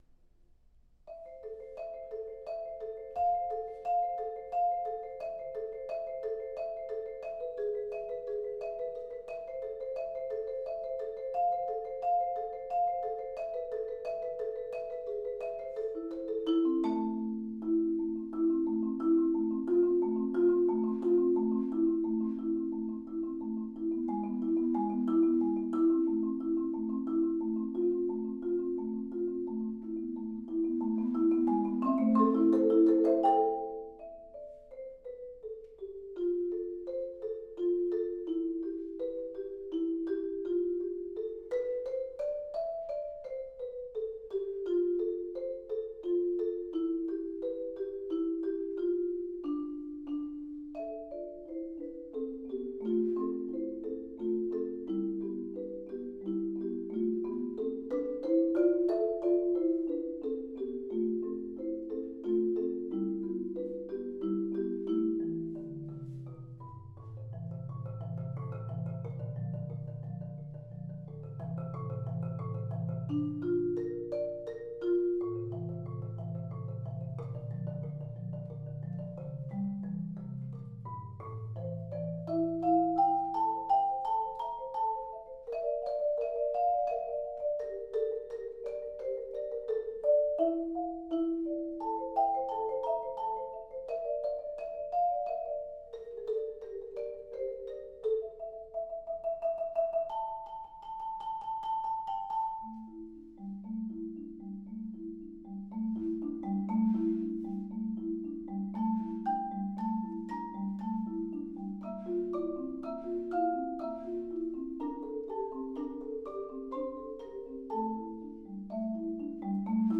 Voicing: Mallet Duet